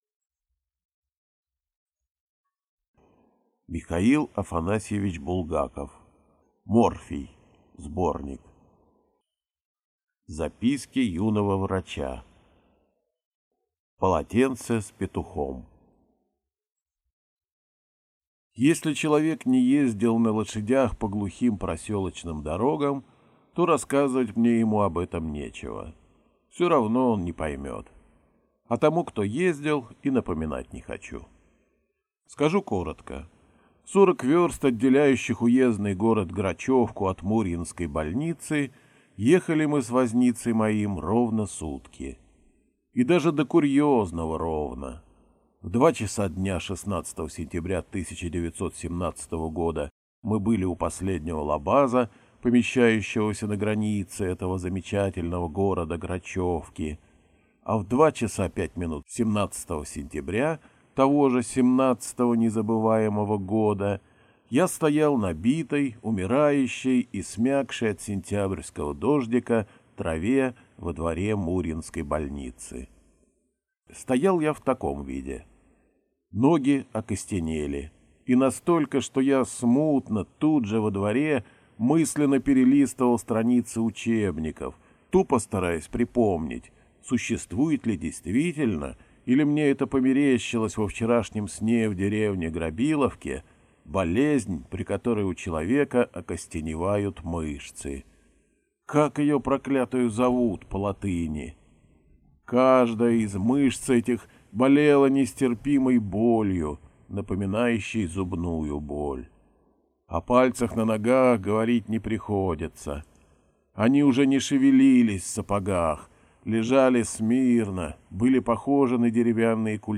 Аудиокнига Морфий (сборник) | Библиотека аудиокниг